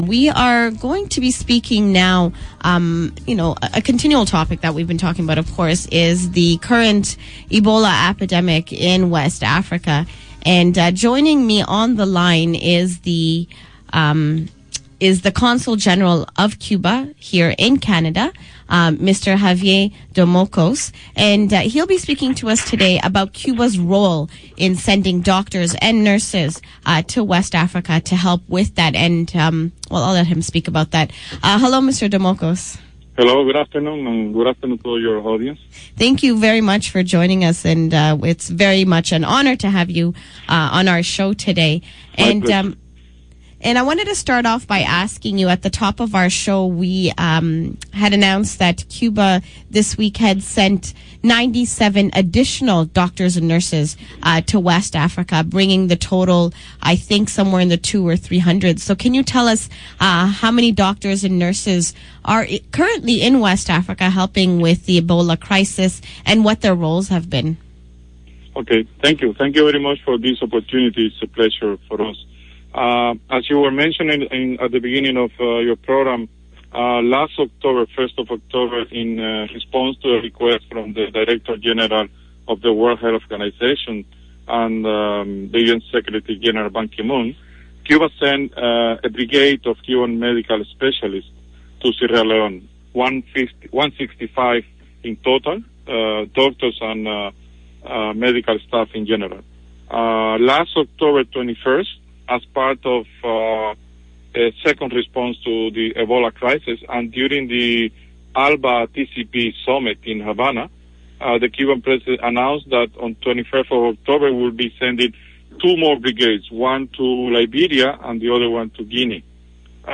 Interview with Mr. Javier Domokos, Consul General of Cuba